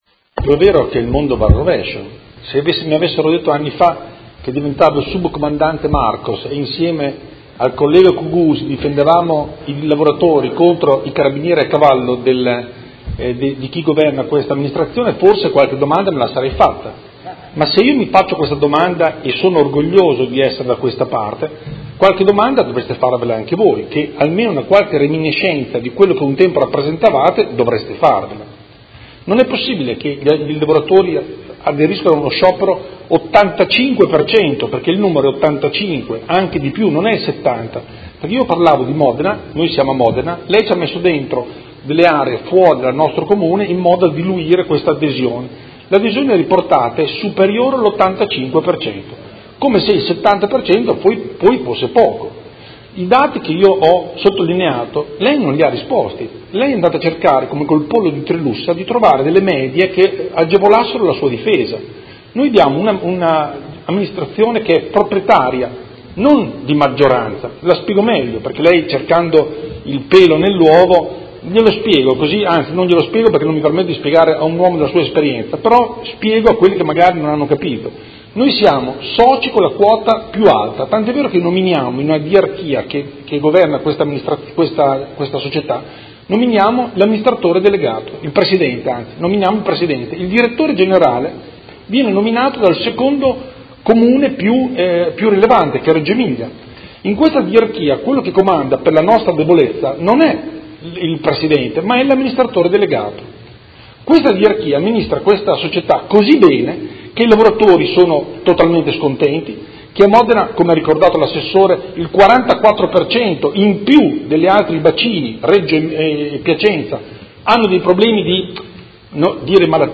Andrea Galli — Sito Audio Consiglio Comunale
Seduta del 13/07/2017 Replica a risposta Assessore.